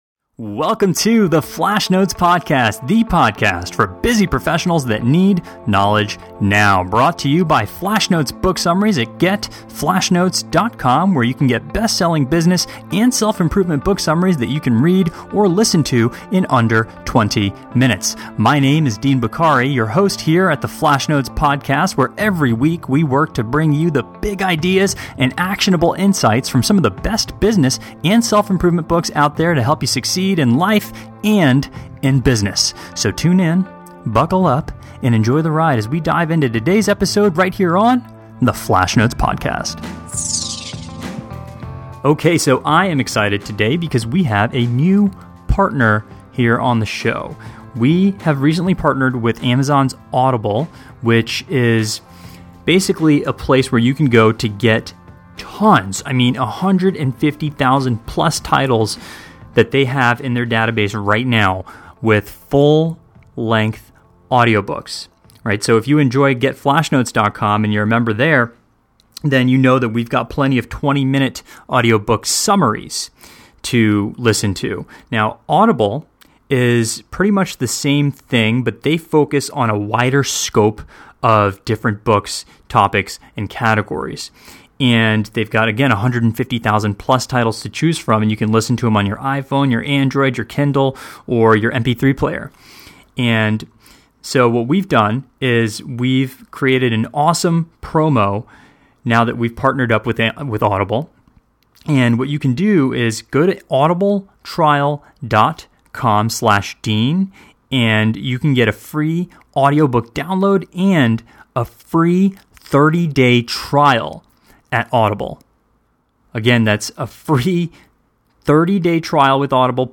Author Interview